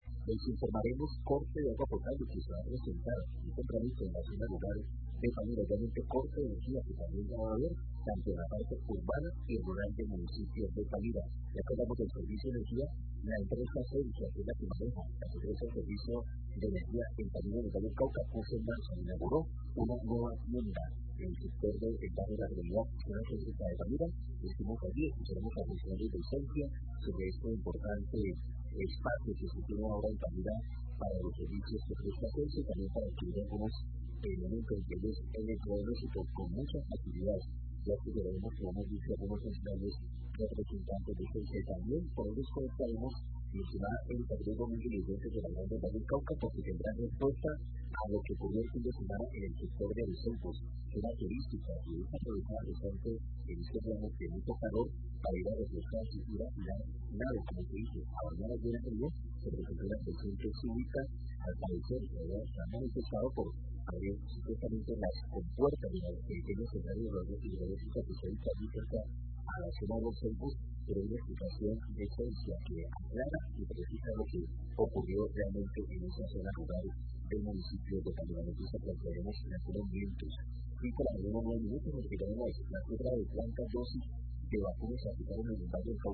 Radio
titulares